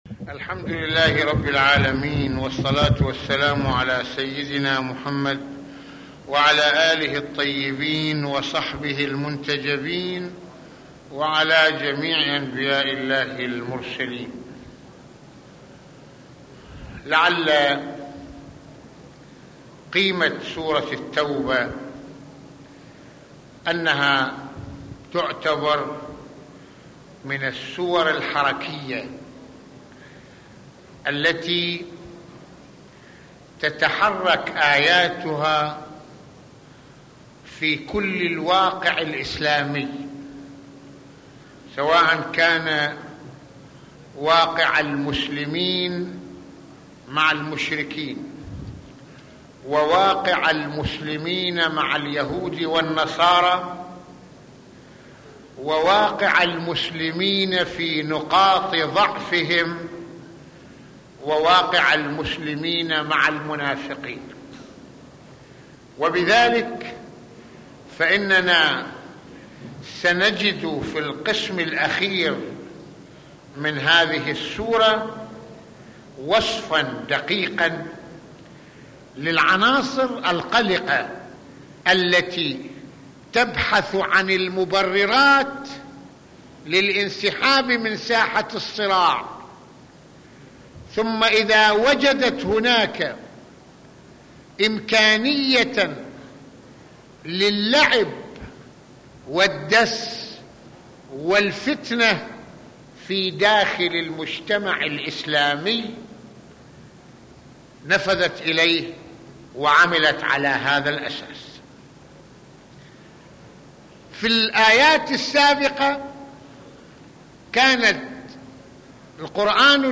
- يتحدث العلامة المرجع السيد محمد حسين فضل الله "رض" في هذه المحاضرة عن طاعة الهوى والأشخاص داعيا إلى تأكيد التوحيد في العبادة عبرالإخلاص له والخضوع له وحده وتأديب المشاعر وتربيتها على صفاء التوحيد وغاياته وصولا إلى نشر دعوة الله في مواجهة الكفر والضلا